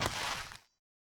PixelPerfectionCE/assets/minecraft/sounds/enchant/thorns/hit2.ogg at 0cc5b581cc6f975ae1bce078afd85fe00e0d032f